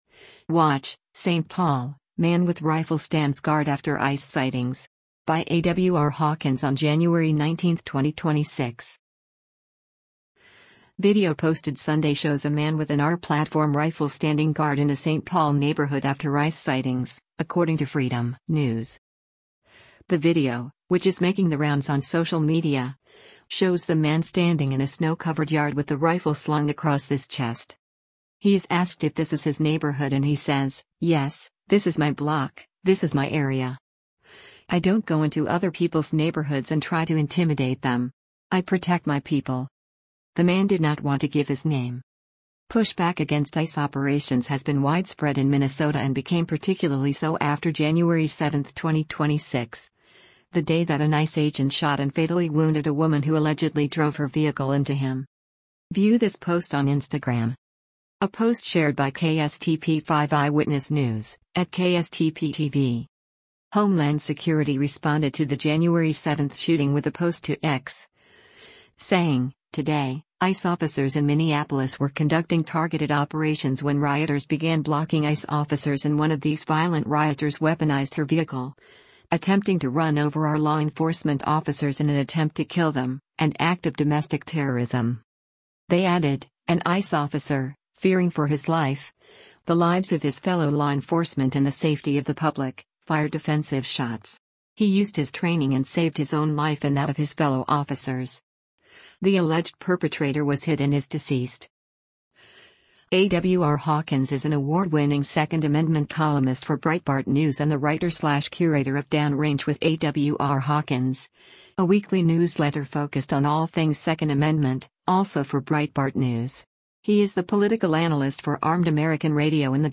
He is asked if this is his neighborhood and he says, “Yes, this is my block, this is my area. I don’t go into other people’s neighborhoods and try to intimidate them. I protect my people.”